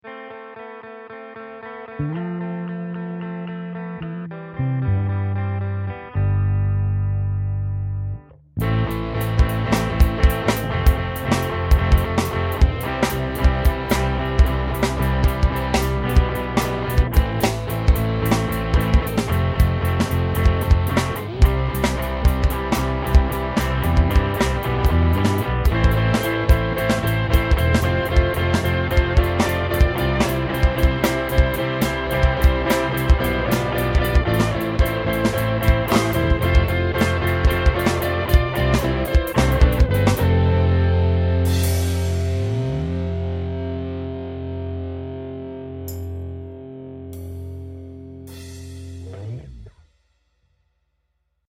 4:4 rock beat